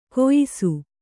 ♪ koyisu